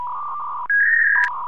win.ogg